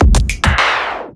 OLIVER_fill_drum_loop_tape_stop_103.wav